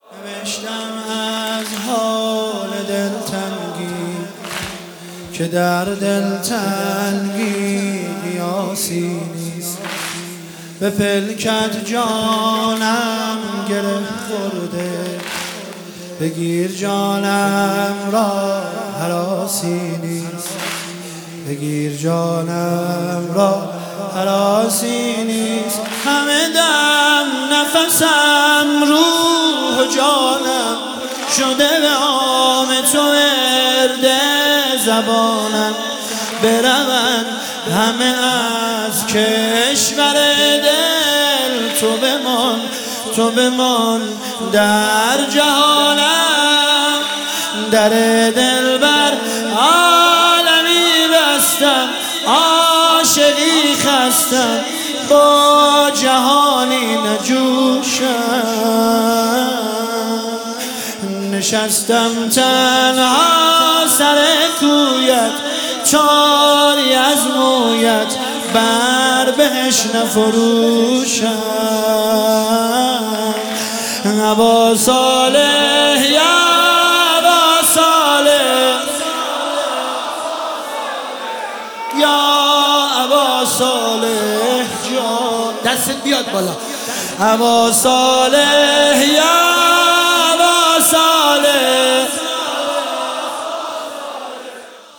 مداحی واحد
حرم شهدای گمنام شهرستان ملارد
فاطمیه دوم 1403